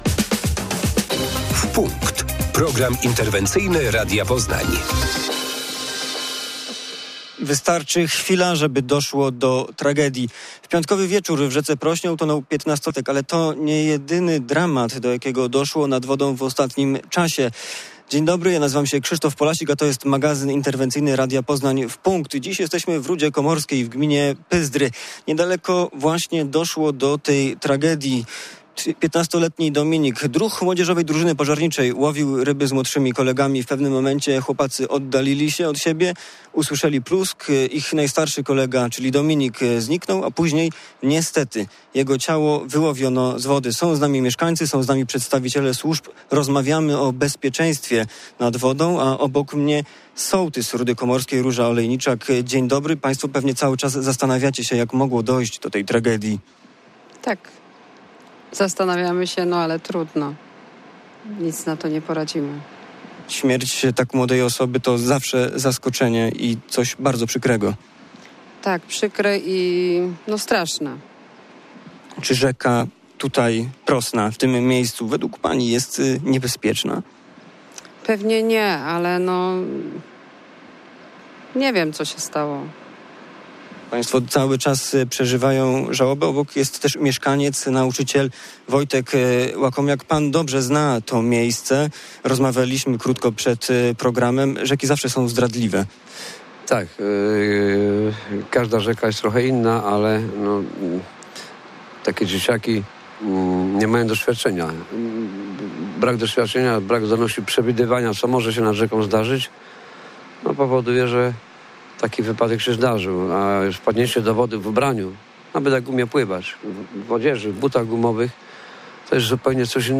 Służby apelują o rozwagę nad wodą. Ostatni przed wakacjami magazyn „W punkt” nadaliśmy z gminy Pyzdry, gdzie w połowie czerwca w rzece utonął 15-latek.